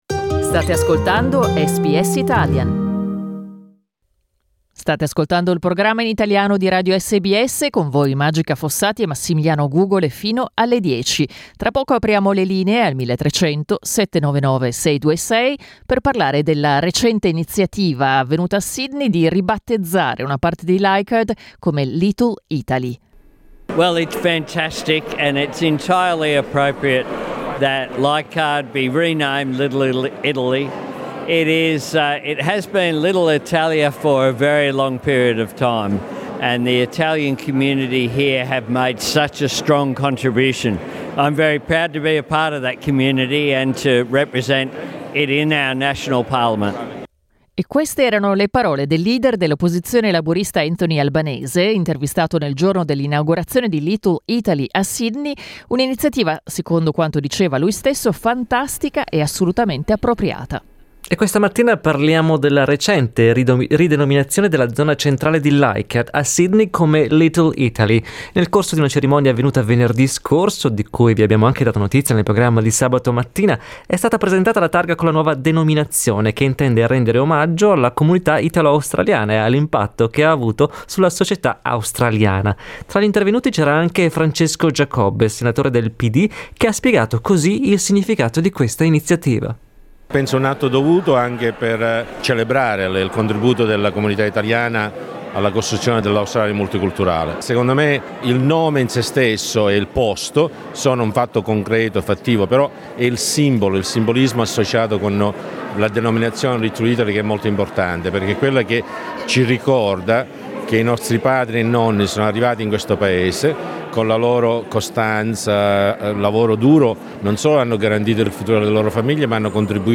Ascolta il dibattito con ascoltatrici e ascoltatori: LISTEN TO "Little Italy", un omaggio appropriato per la comunità italoaustraliana?